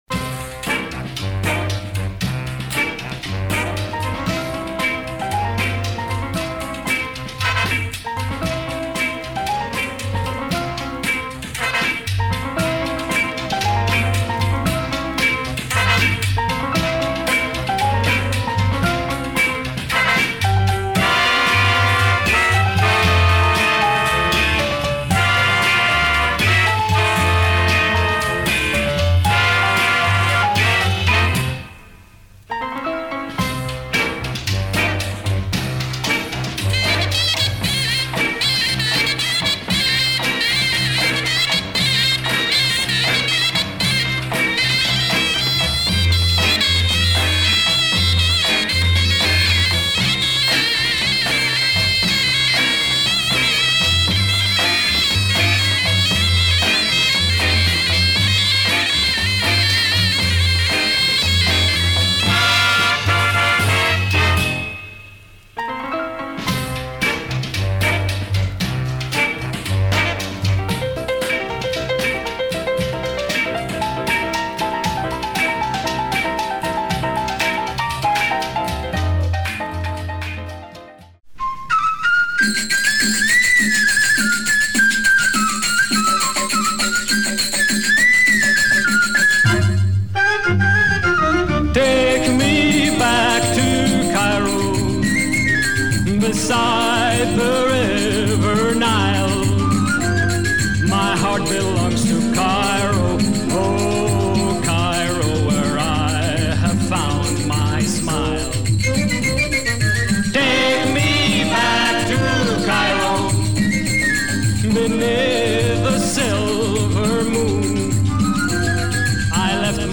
oriental jazz from the mid 70’s
an exquisite vocal track
the instrumental version